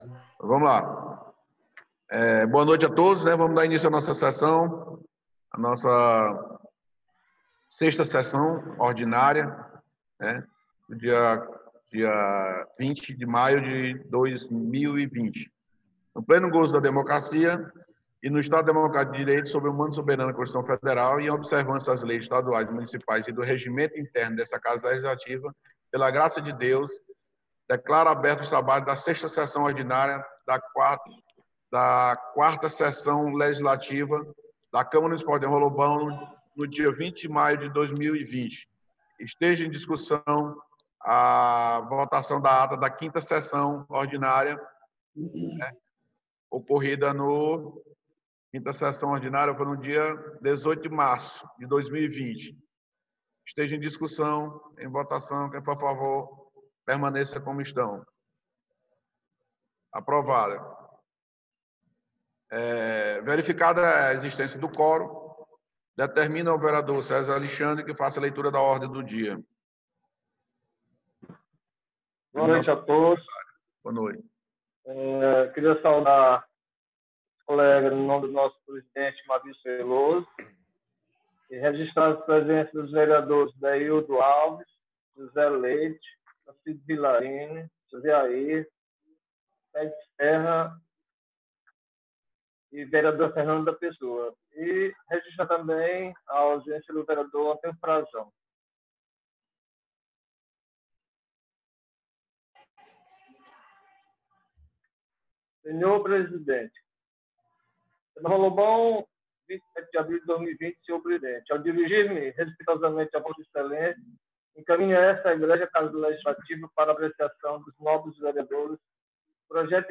6ª Sessão Ordinária 20 de Maio